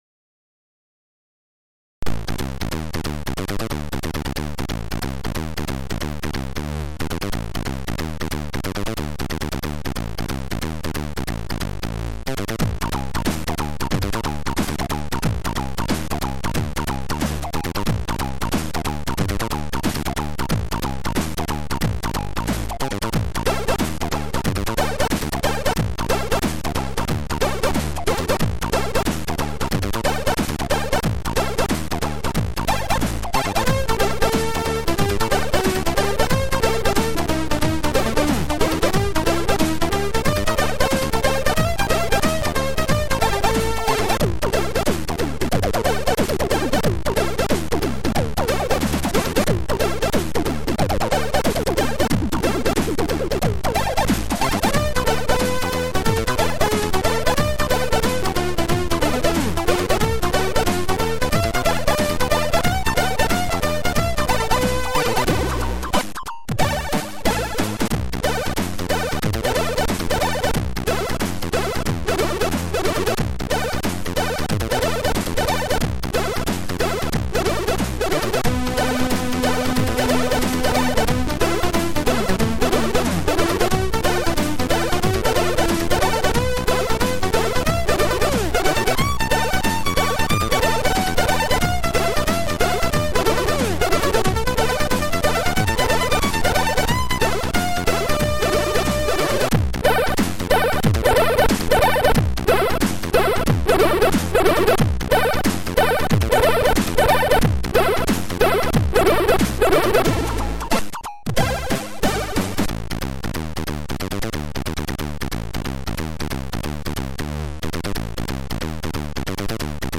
Sound Format: C64 SID